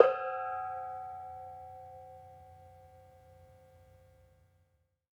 Gamelan Sound Bank
Bonang-C4-f.wav